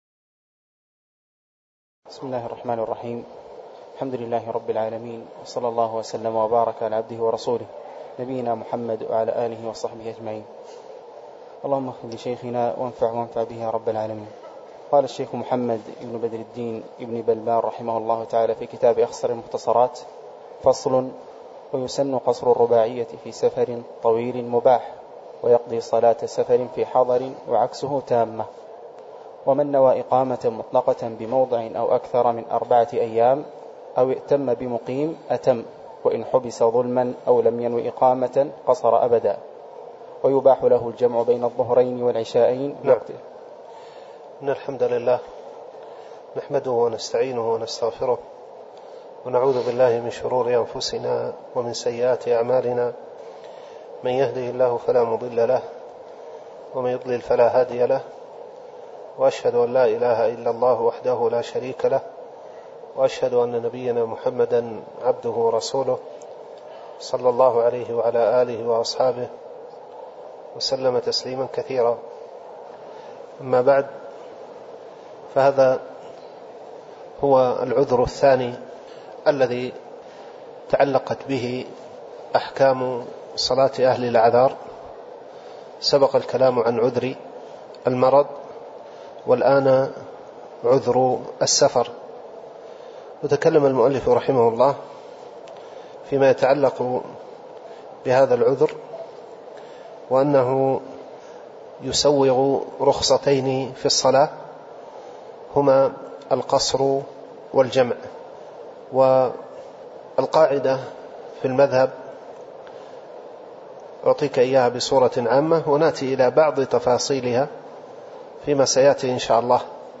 تاريخ النشر ١١ رجب ١٤٣٩ هـ المكان: المسجد النبوي الشيخ